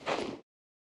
equip_generic3.ogg